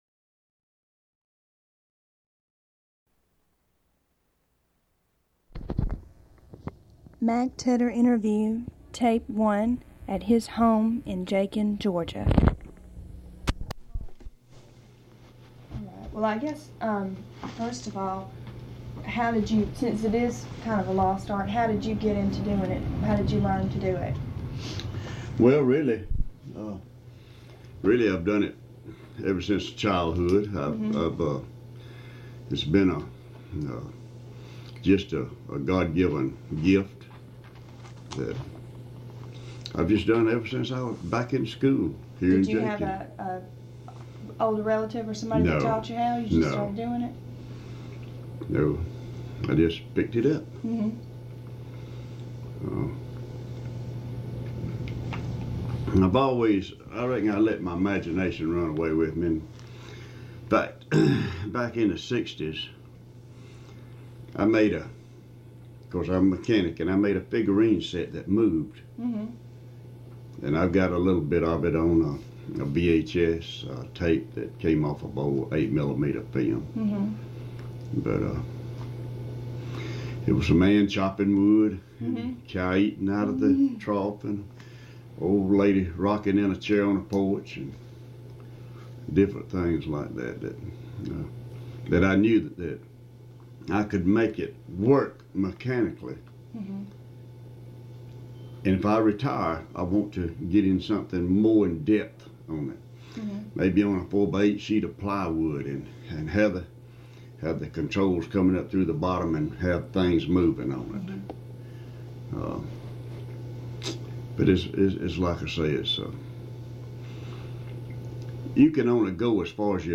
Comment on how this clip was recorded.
Jakin, Georgia [Early County].